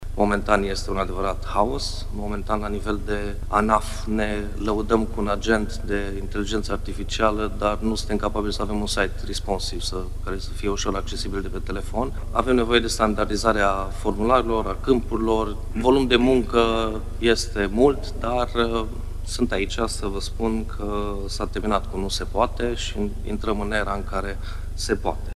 Digitalizarea statului român este greoaie și copleșită: cu greu se poate discuta despre implementarea inteligenței artificiale în procese, dacă nu rezolvăm primele probleme, afirmă președintele Comisiei pentru Comunicații și Tehnologie din Senat, la o conferință organizată de publicația CursDeGuvernare.